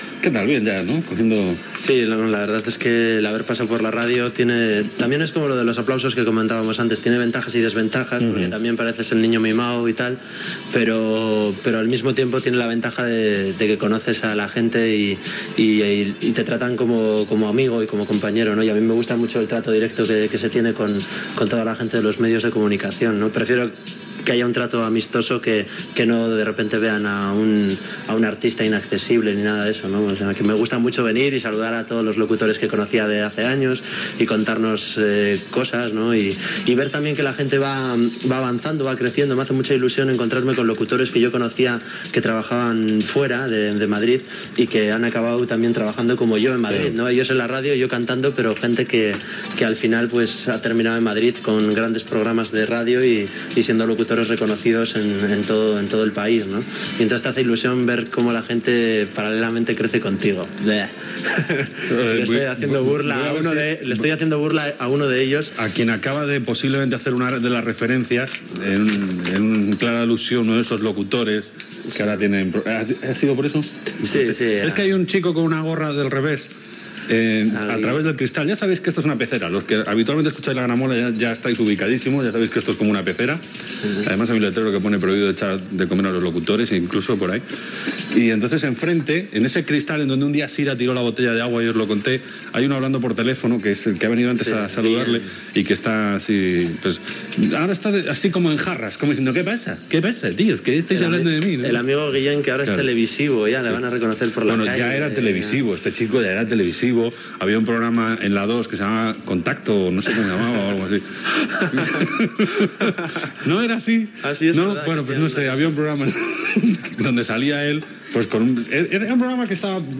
Entrevista al cantant Tontxu Ipiña que presenta el seu disc